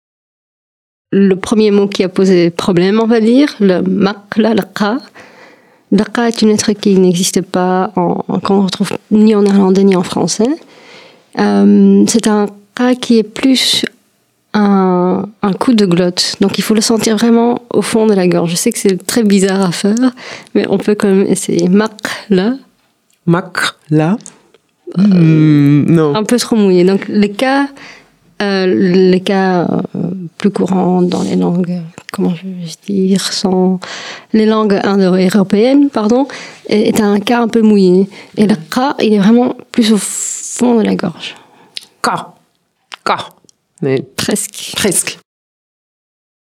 prononciation prononciation prononciation